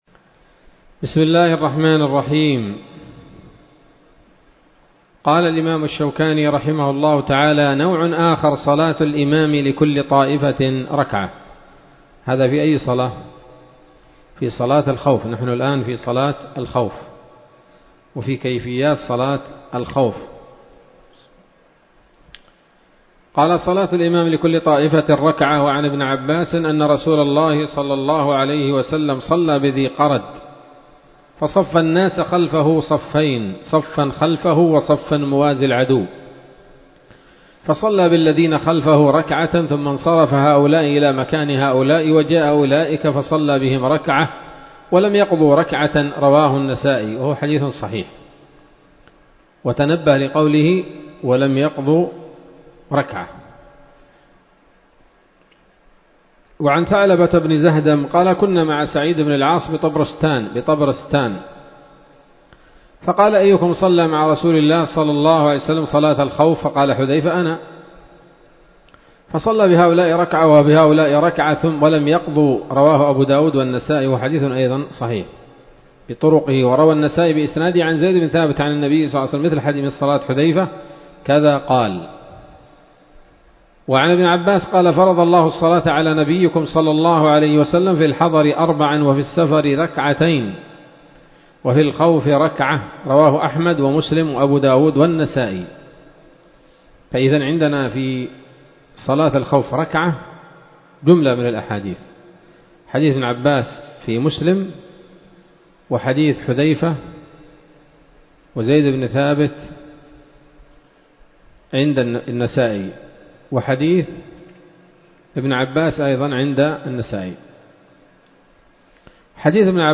الدرس الخامس من ‌‌‌‌كتاب صلاة الخوف من نيل الأوطار